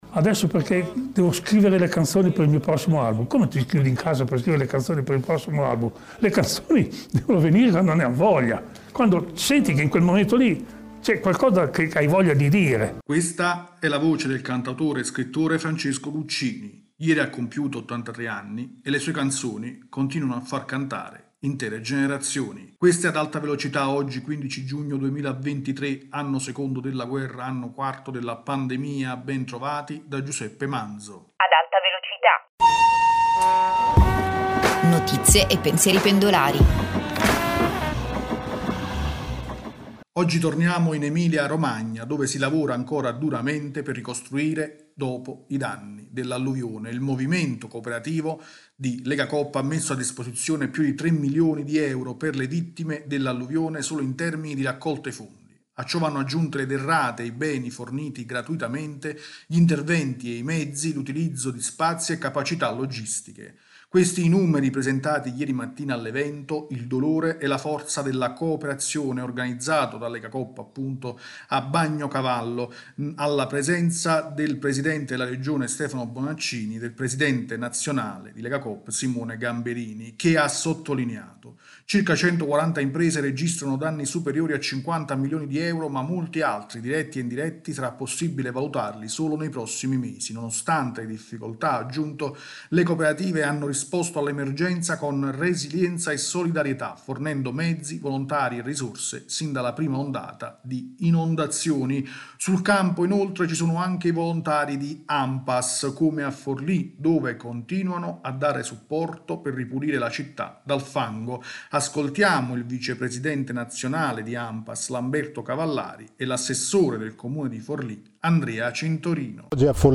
rubrica quotidiana